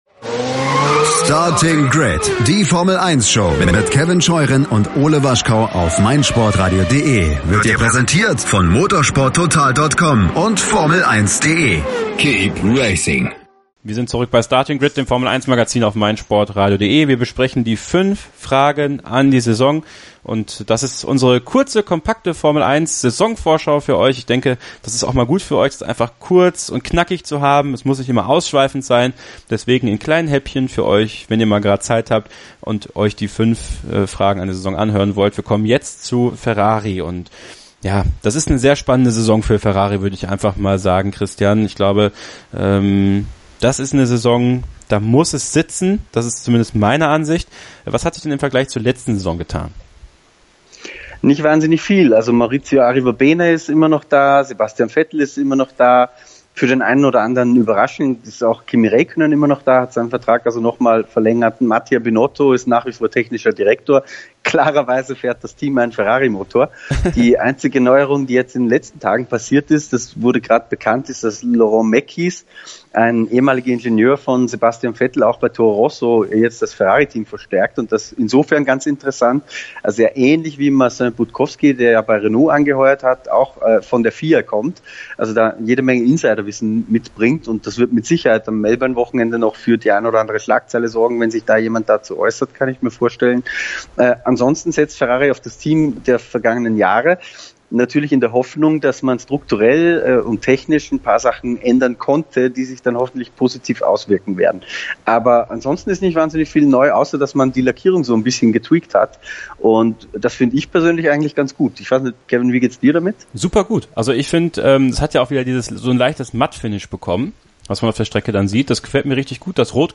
Gemeinsam werden diese ausdiskutiert und in einem lockeren